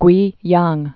(gwēyäng, gwā-) also Kwei·yang (kwā-)